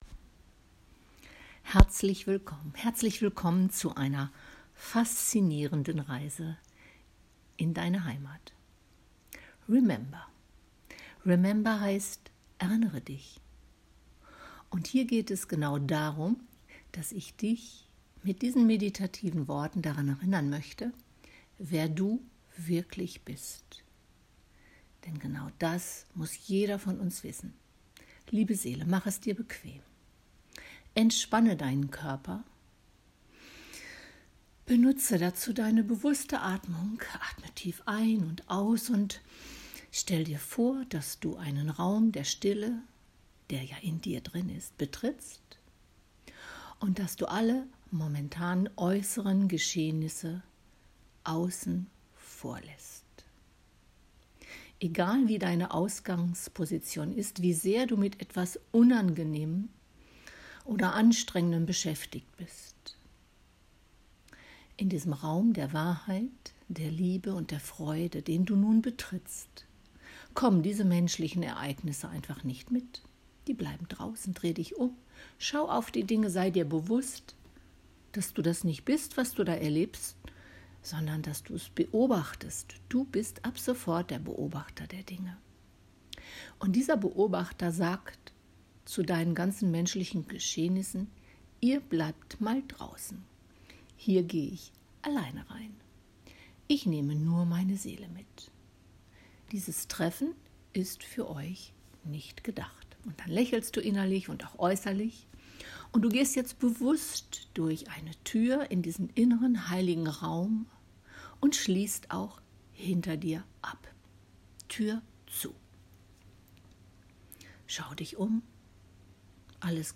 Meditation-remember.mp3